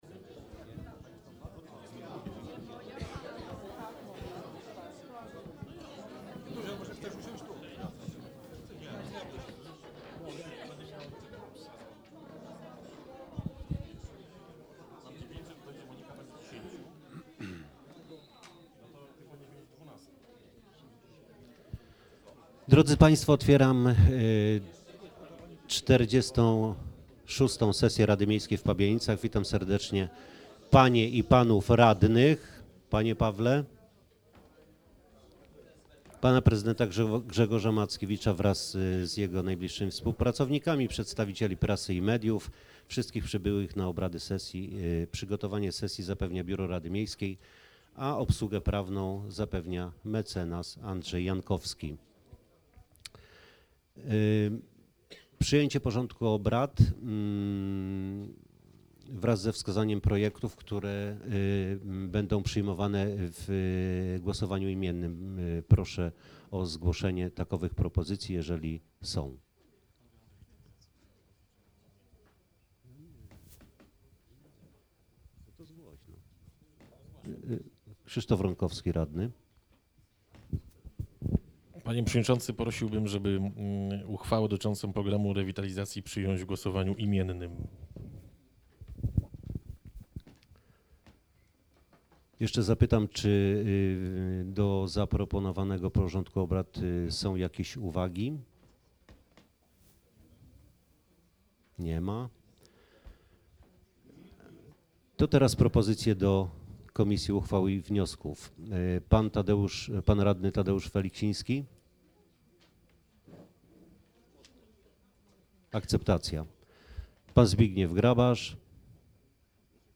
XLVI sesja Rady Miejskiej w Pabianicach - 19 września 2017 r. - 2017 rok - Biuletyn Informacji Publicznej Urzędu Miejskiego w Pabianicach